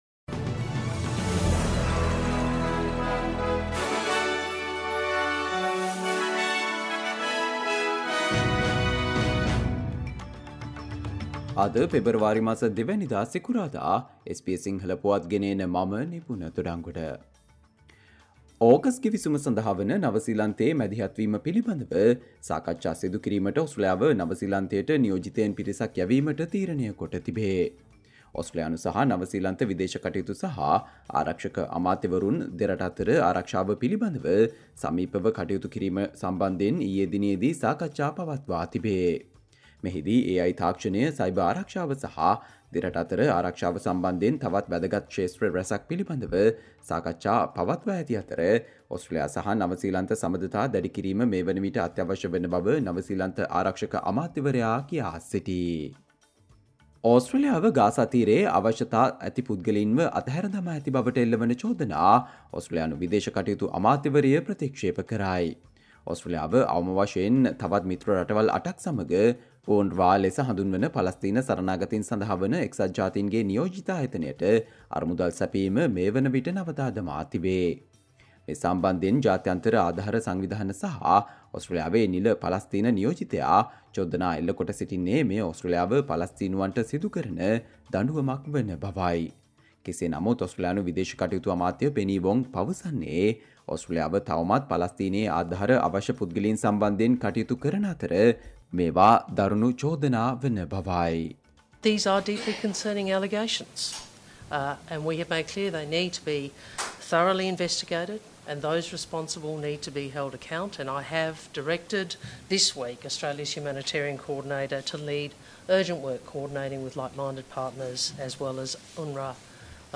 Australia news in Sinhala, foreign and sports news in brief - listen, Friday 02 February 2024 SBS Sinhala Radio News Flash